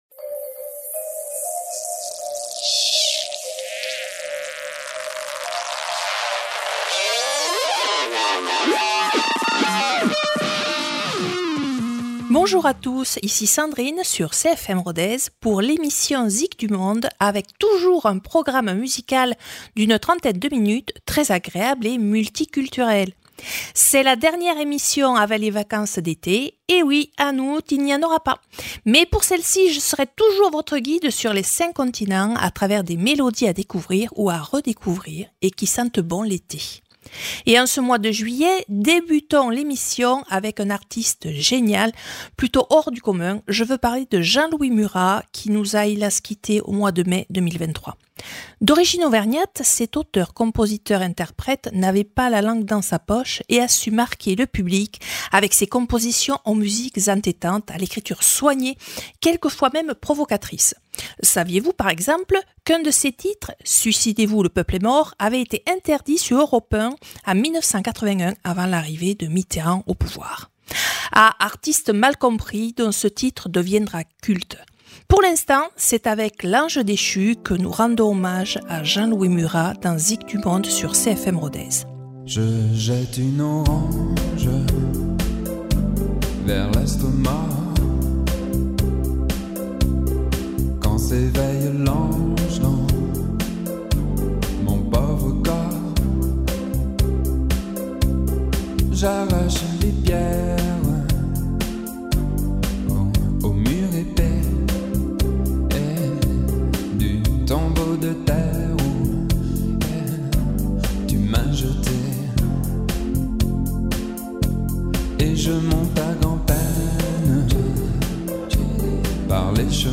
C’est la dernière émission avant l’été. Fêtons cette belle saison avec un tour du monde en musique des plus estivals.